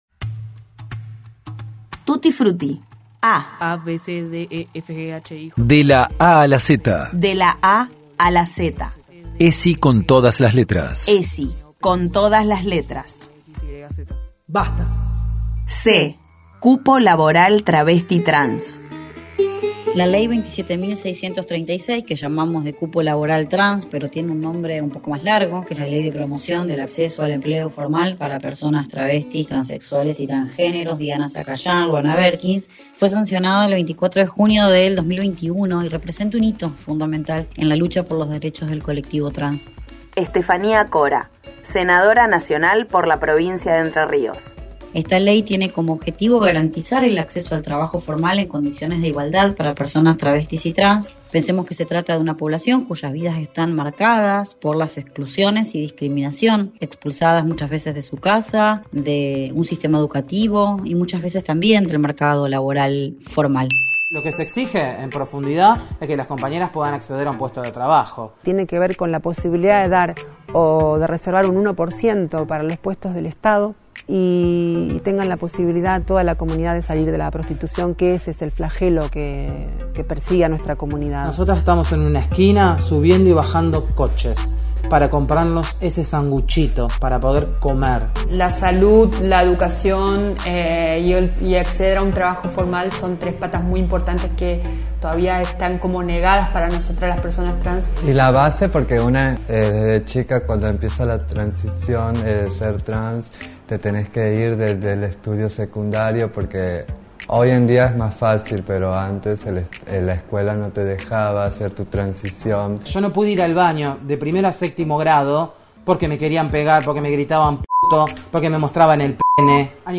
La Ley Diana Sacayán – Lohana Berkins. La senadora nacional por Entre Ríos Estefanía Cora analiza esta norma que garantiza el acceso al trabajo formal.